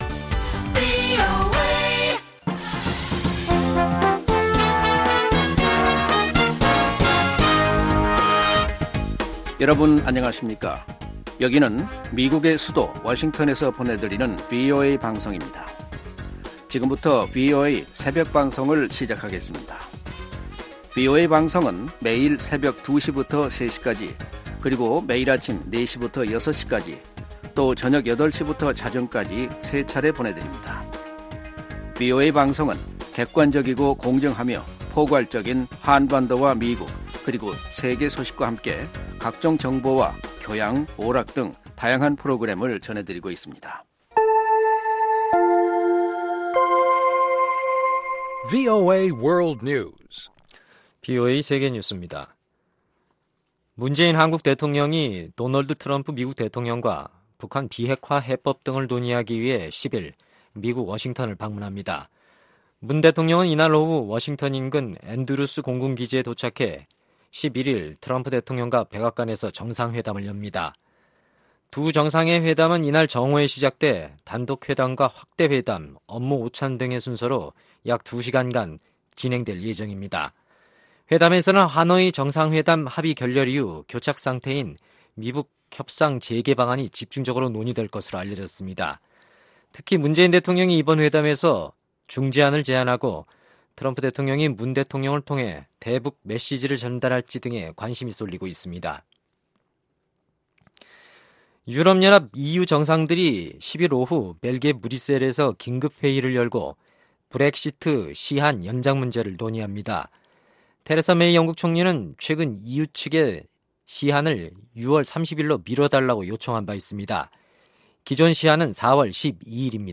VOA 한국어 '출발 뉴스 쇼', 2019년 4월 11일 방송입니다. 마이크 폼페오 미 국무장관이 미-한 정상회담을 앞두고 열린 의회 청문회에서 미국의 대북 접근법을 다시 강조했습니다. 미국 재무부가 북한과 이란 등의 불법 금융활동을 차단하기 위해 내년 회계연도의 관련 예산을 1천 4백 60만 달러 이상 늘린 2억 9천 141만 달러로 책정했습니다.